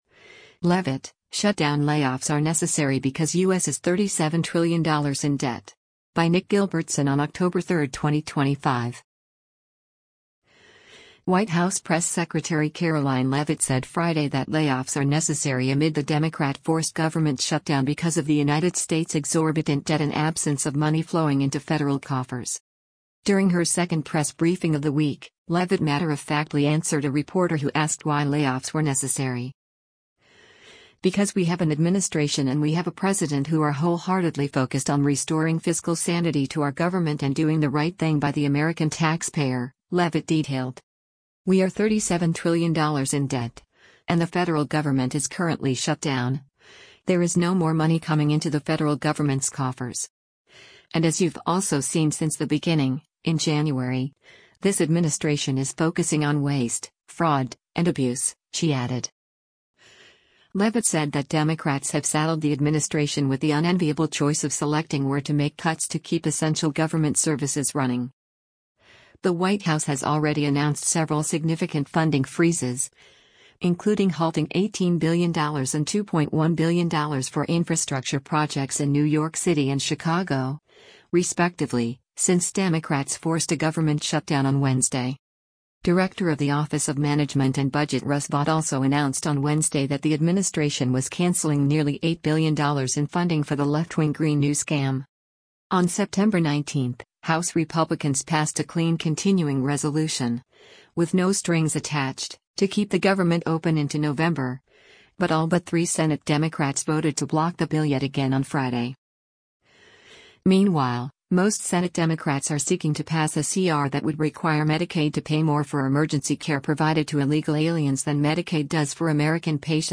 During her second press briefing of the week, Leavitt matter-of-factly answered a reporter who asked why layoffs were necessary.